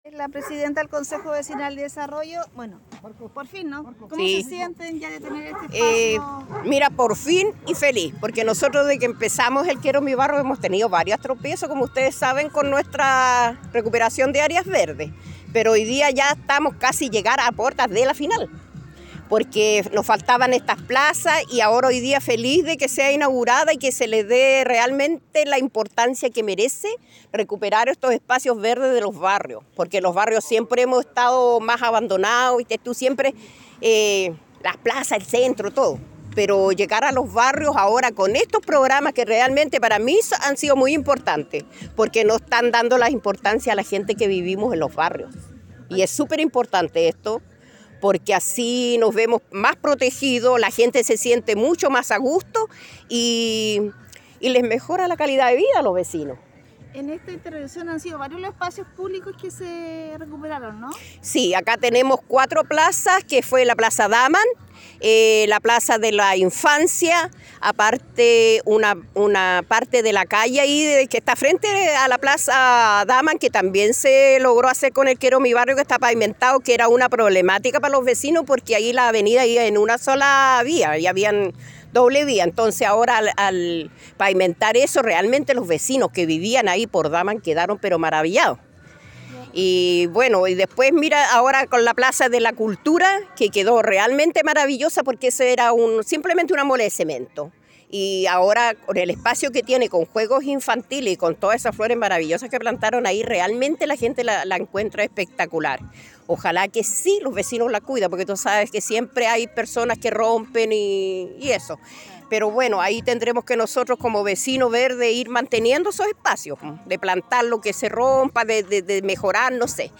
cuña pdta cvd-delegado y seremi